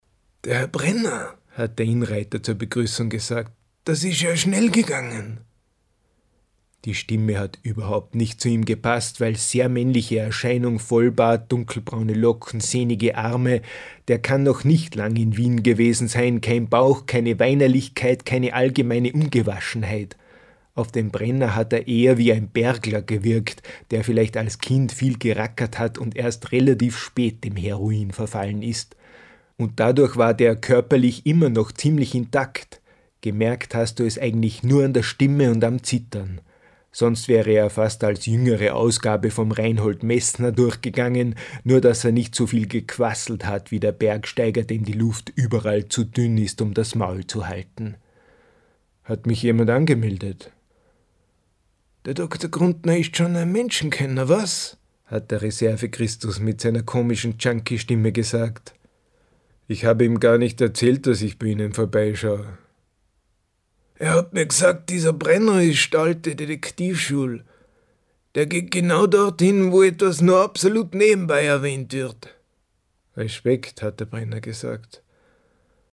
» Back to Speech – Audio Books
recorded, mixed and mastered at Amann Studios